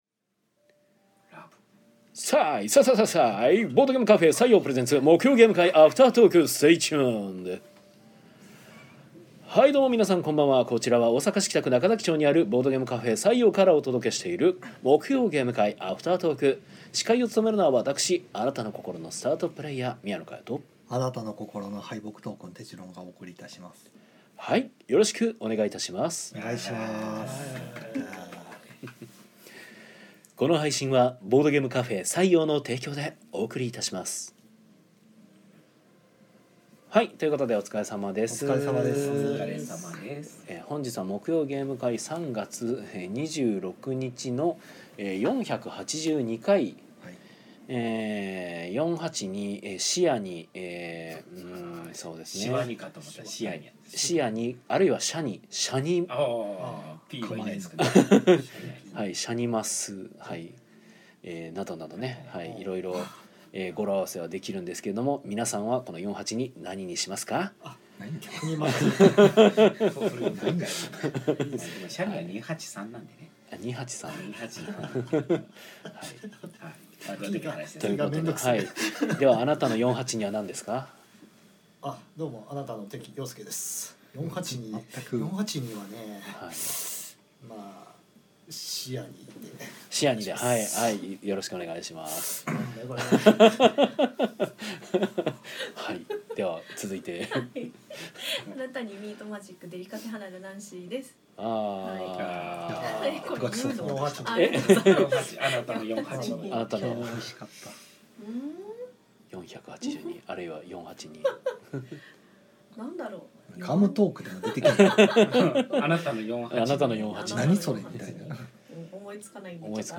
ゲーム会の話や、近況などをダラダラと生配信で垂れ流したものを鮮度そのままノーカットでパッケージング！（podcast化）